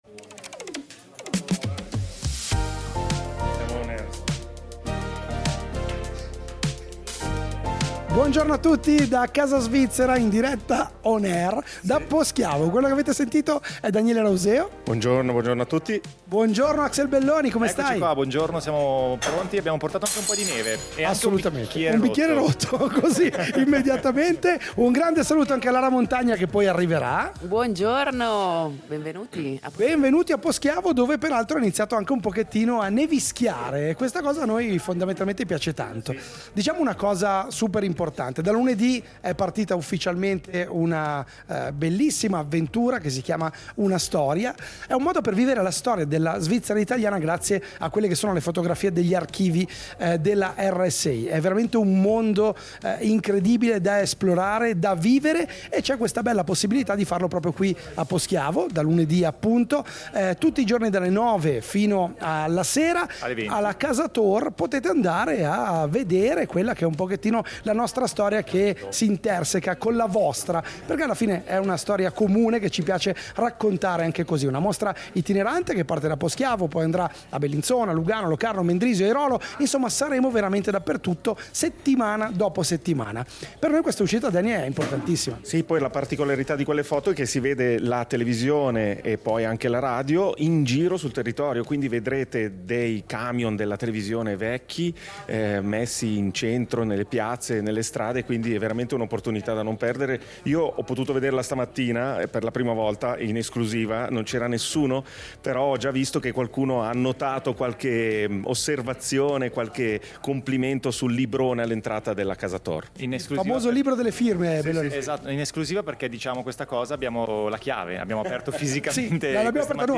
Val Poschiavo in diretta